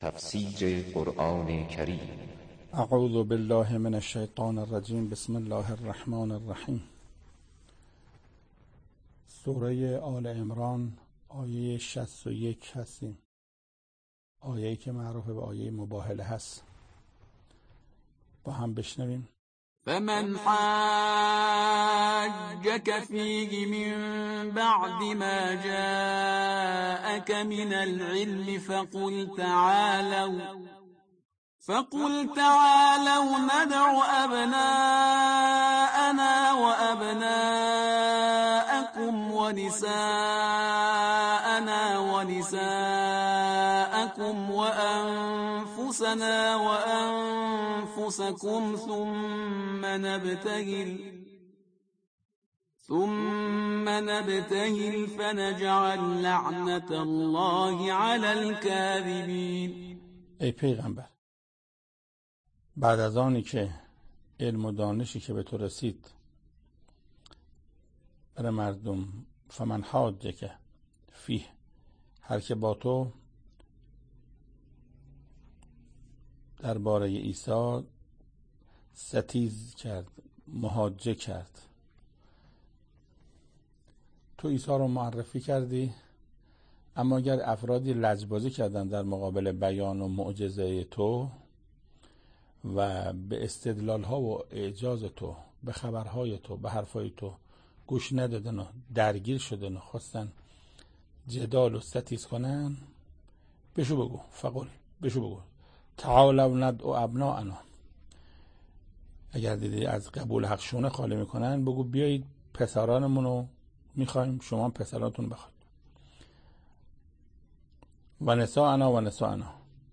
تفسیر آیه 61 سوره آل عمران - استاد محسن قرائتی در این بخش از ضیاءالصالحین، صوت تفسیر آیه شصت و یکم سوره مبارکه آل عمران را در کلام حجت الاسلام استاد محسن قرائتی به مدت 14 دقیقه با شما قرآن دوستان عزیز به اشتراک می گذاریم.